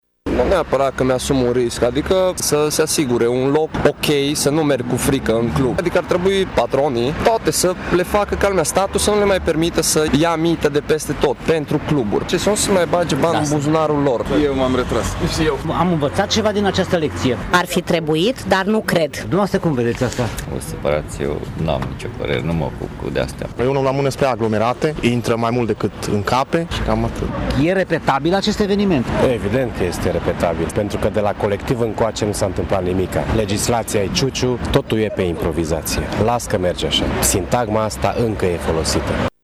În general, oamenii acuză atât neglijența patronilor de cluburi cât și nerespectarea legislației, sau evită să comenteze pe această temă: